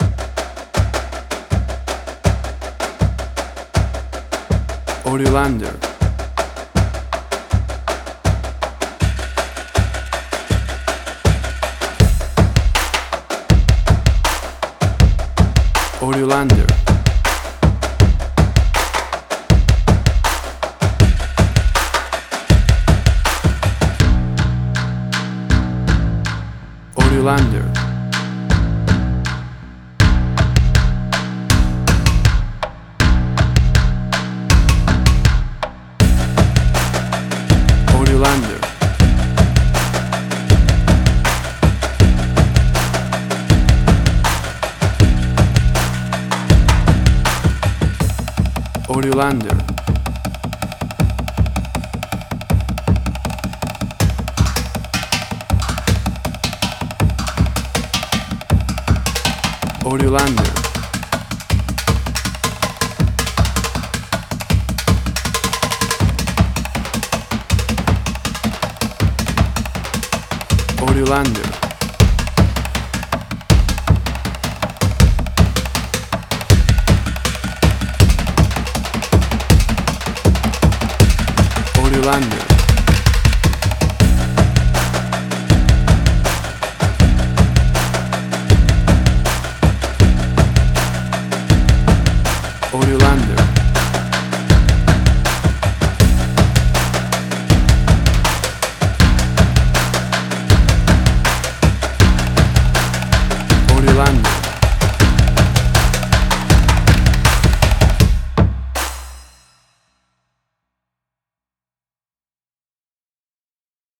Tempo (BPM): 80